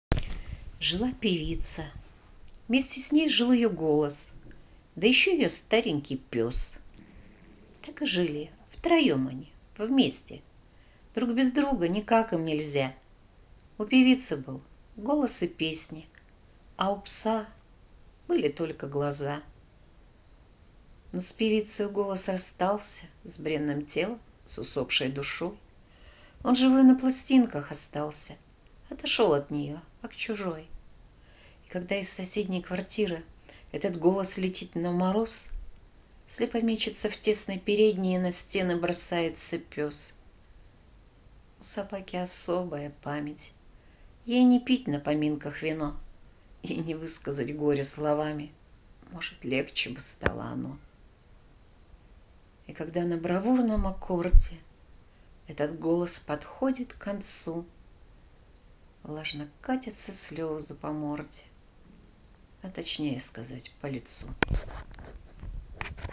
2. «Автор – Николай Доризо – Собака Эдит Пиаф , “Жила певица…”» /